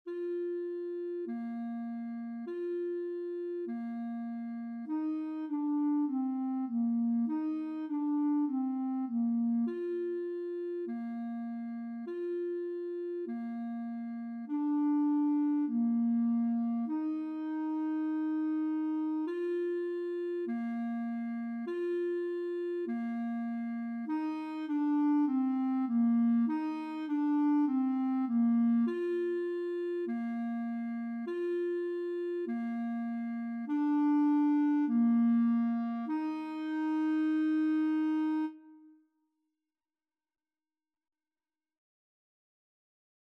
4/4 (View more 4/4 Music)
Bb4-F5
Clarinet  (View more Beginners Clarinet Music)
Classical (View more Classical Clarinet Music)